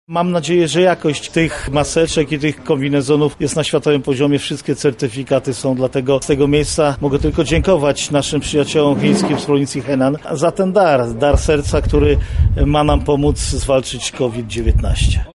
-mówi Marszałek Województwa Lubelskiego Jarosław Stawiarski.